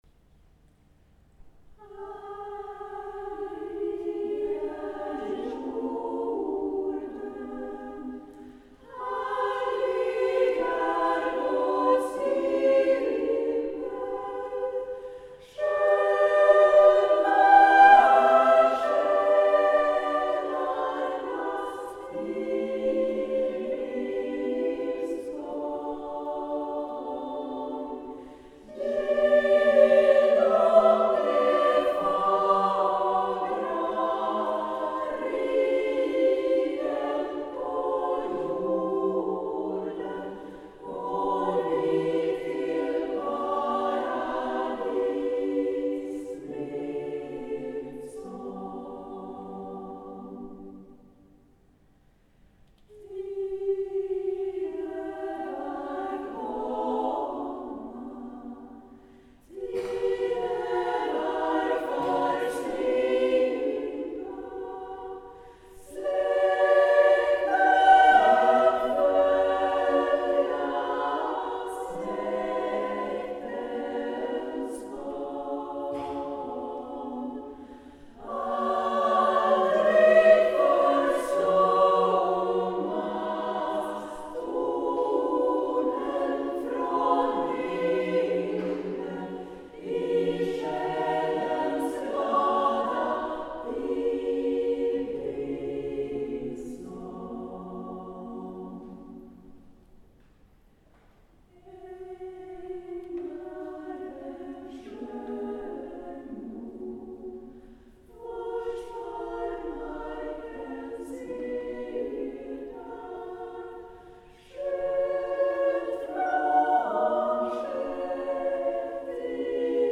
Kristinakören är, vad vi vet, Sveriges äldsta damkör.
Hör oss sjunga
Härlig är jorden (mp3-fil 2,9MB, live-inspelning från Passionkirche, Berlin)